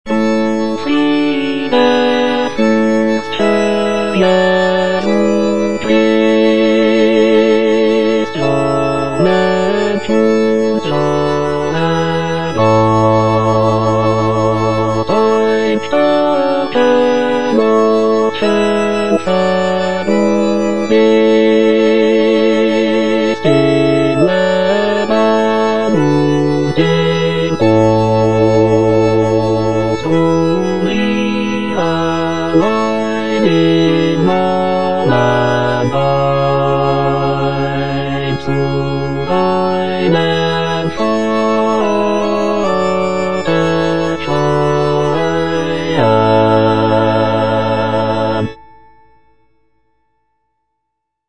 Cantata
Bass (Emphasised voice and other voices) Ads stop